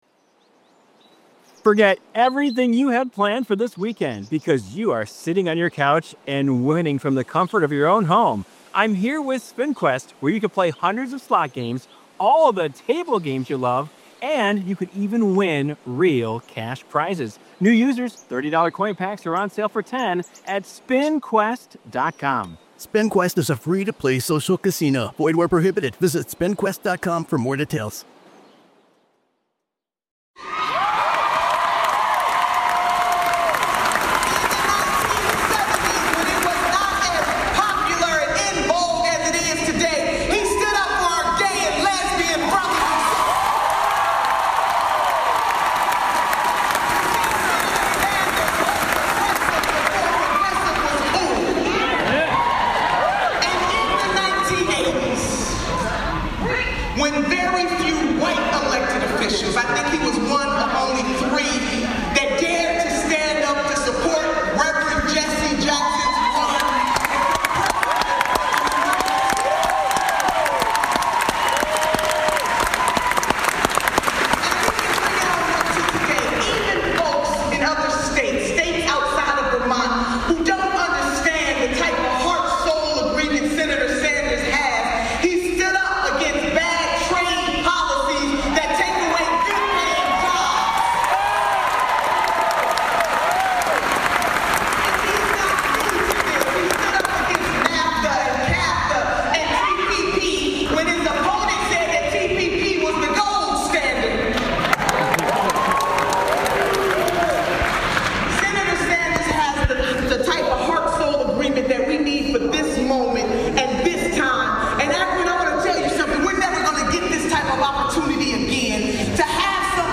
Lousy Weather Media has their own “exclusive” with OVER 30 minutes of DIY Bernie Sanders Audio recorded at the Akron Rally for Bernie Sanders at the Civic Theater. A couple thousand people attended this rally in less than a days notice and we were there to capture it for you.